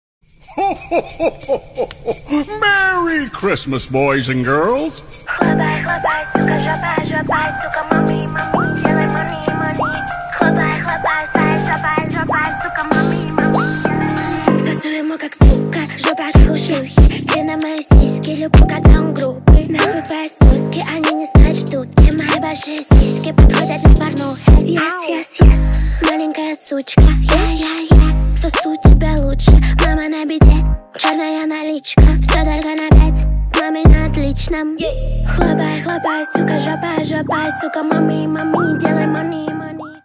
Ремикс
Рэп и Хип Хоп
громкие